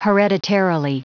Prononciation du mot hereditarily en anglais (fichier audio)
Prononciation du mot : hereditarily